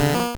Cri de Ramoloss dans Pokémon Or et Argent.